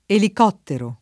elicottero [ elik 0 ttero ] s. m.